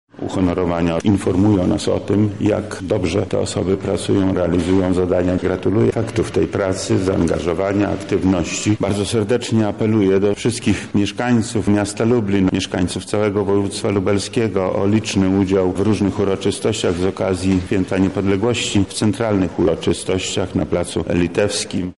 Z tej okazji w Lubelskim Urzędzie Wojewódzkim odbyło się dziś (10.11) uroczyste wręczenie odznaczeń państwowych i resortowych.
Nagrody to przede wszystkim docenienie lat pracy, ale nie tylko – mówi wojewoda lubelski Lech Sprawka: